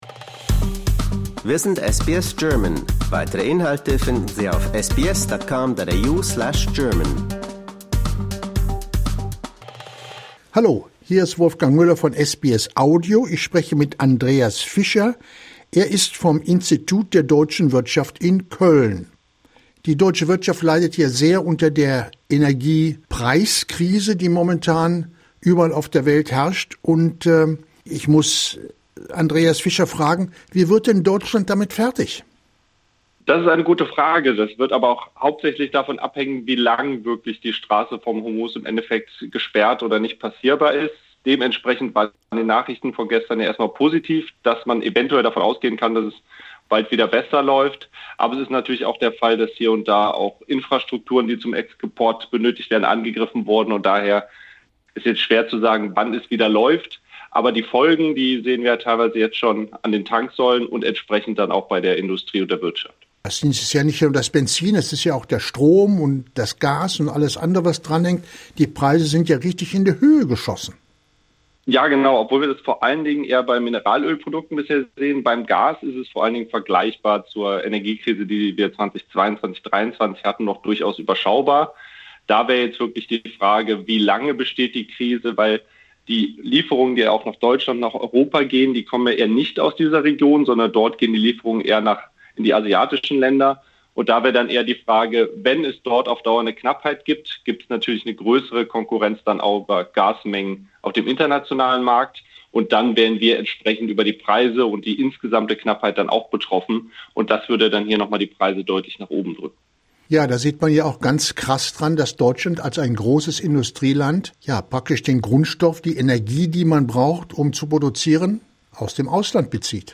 Disclaimer: We would like to point out that the opinions expressed in this article represent the personal views of the interviewed/interlocutor.